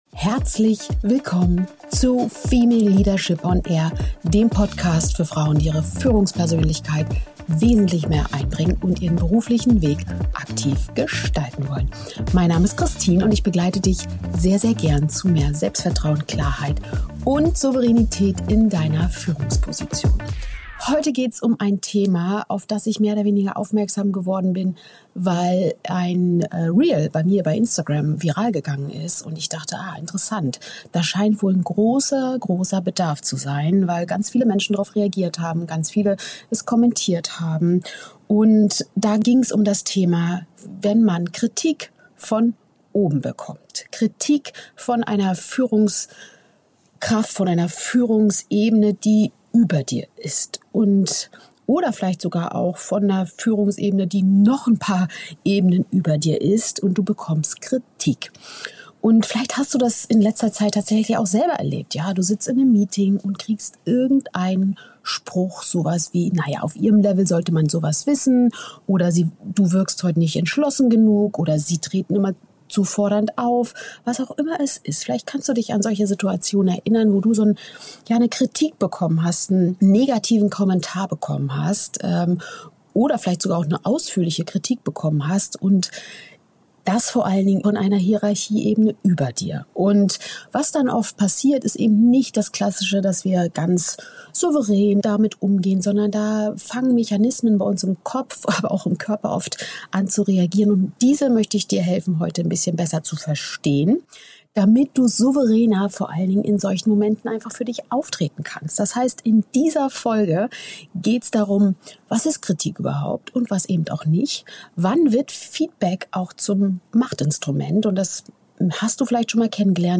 In dieser Solo-Folge von Female Leadership On Air erfährst du: Warum Kritik von Hierarchieebenen über dir besonders stark wirkt Wann Feedback wertvoll ist und wann es zum Machtinstrument wird Was in deinem Gehirn in solchen Momenten passiert Warum Frauen...